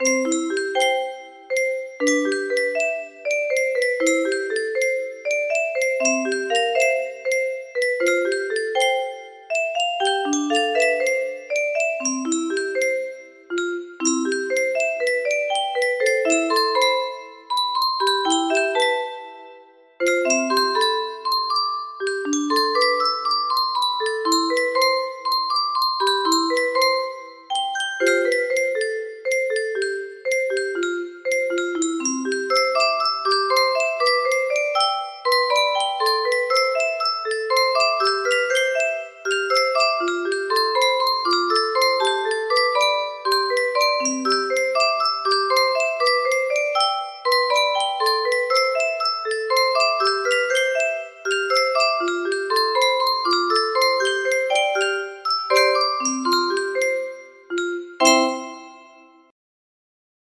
First love music box melody
Grand Illusions 30 (F scale)